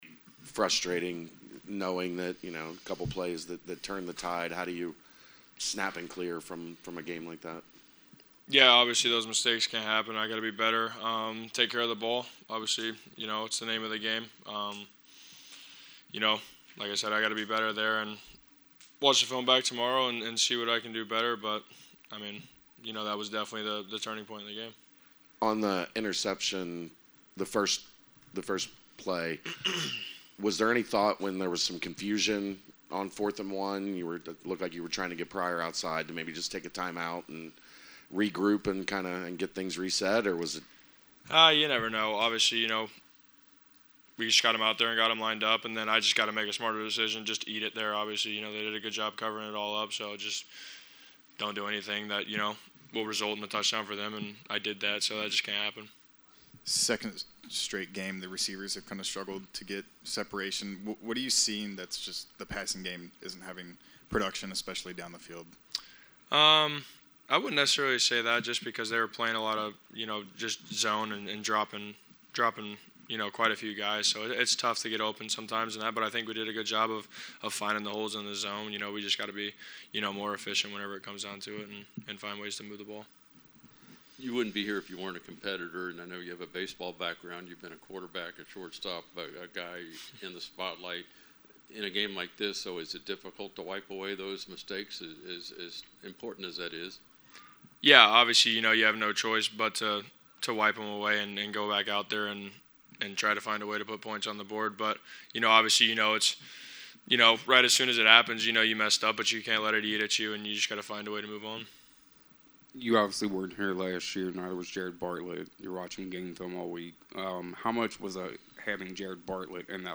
Full Postgame Press Conference